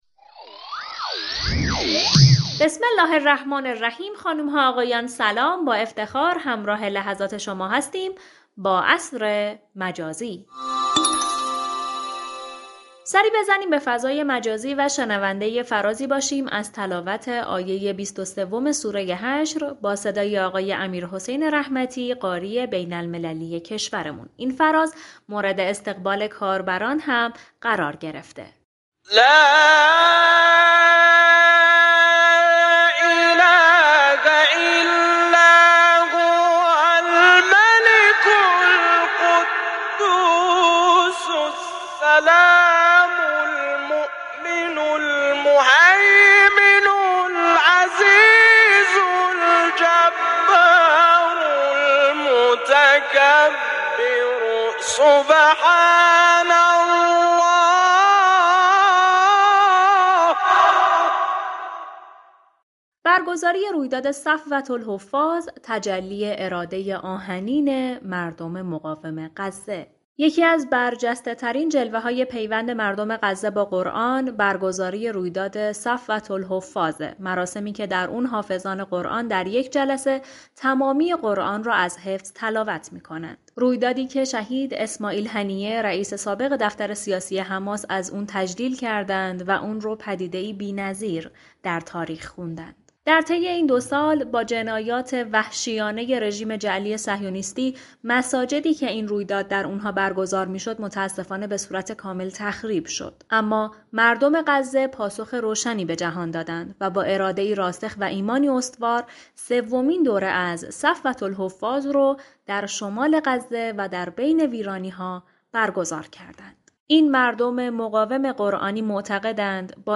به گزارش پایگاه اطلاع رسانی رادیو قرآن ،بخش تلاوت قرآنی برنامه «عصر مجازی» با اجرای فرازی از تلاوت آیه 23 سوره حشر